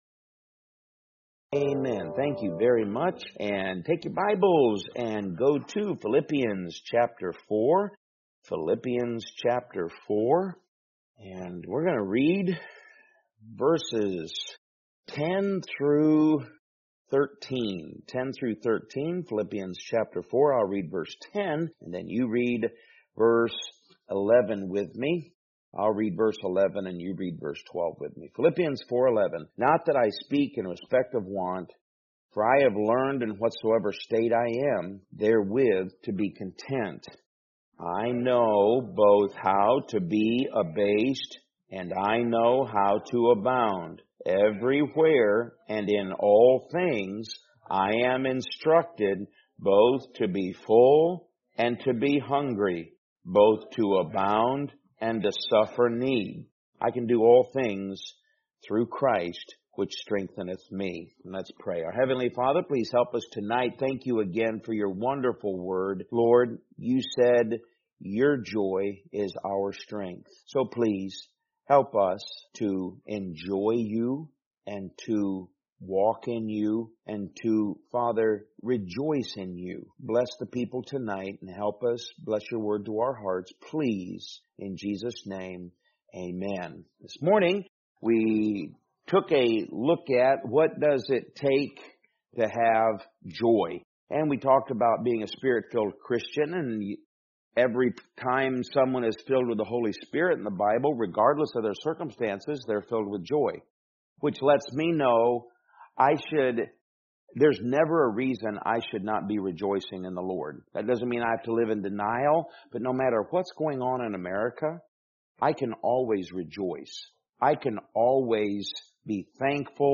Podcast (sermon-podcast): Play in new window | Download